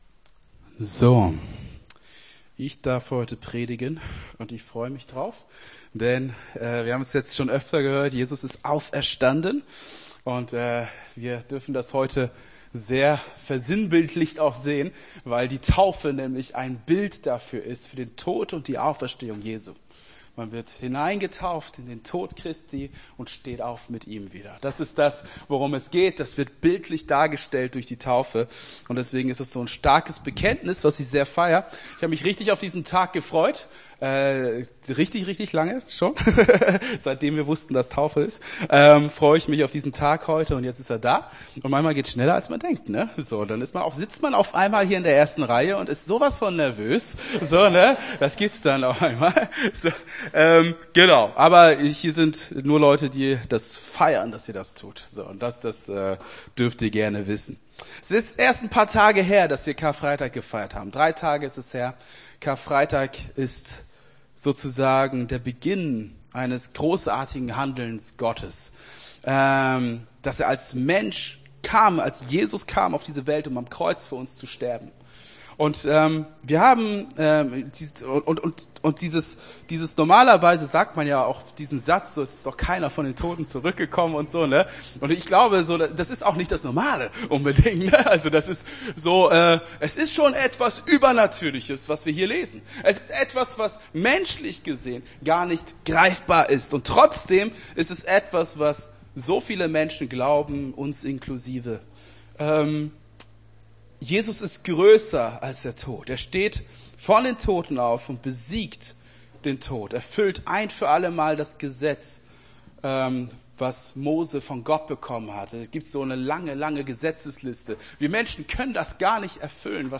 Ostergottesdienst 31.03.24 - FCG Hagen